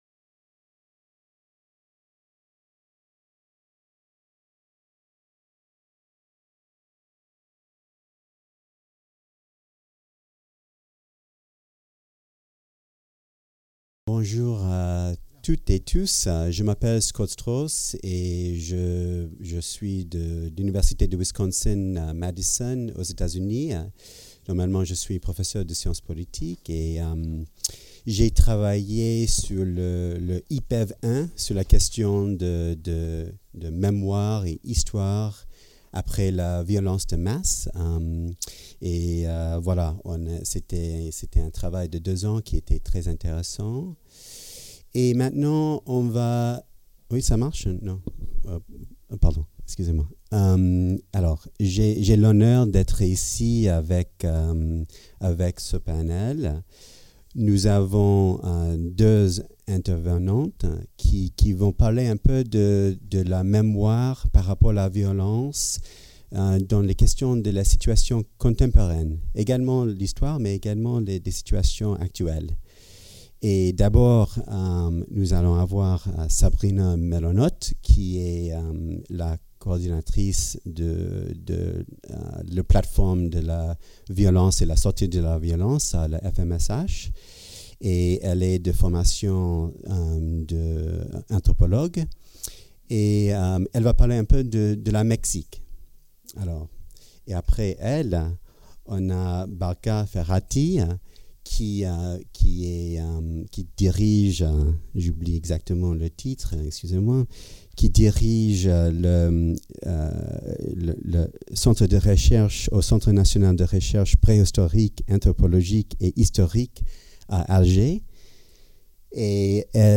Héritage et mémoires contemporaines de violences - Colloque international Rabat 2019 | Canal U